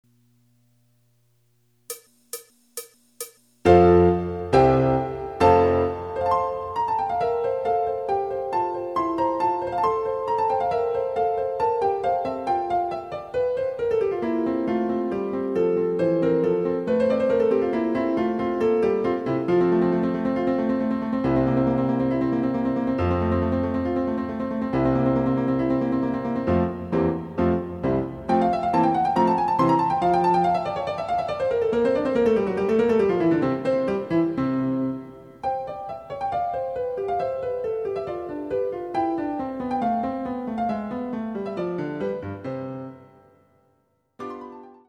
デジタルサンプリング音源使用
※ヴァイオリン奏者による演奏例は収録されていません。